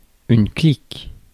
Ääntäminen
IPA : /ə.ˈkʌm.pə.ni.mənt/